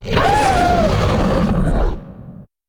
die1.ogg